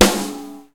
soft-hitclap2.ogg